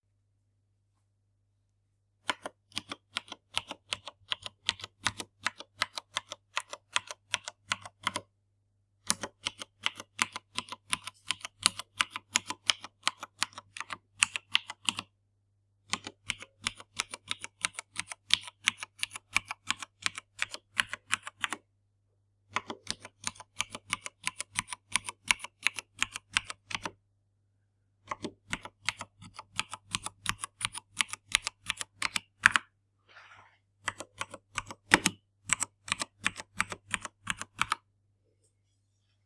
A Keychron K2 inspired keyboard component with optional haptics and mechanical sound effects.
Turns mechanical key sound playback on or off.
Path to the keyboard audio sprite file.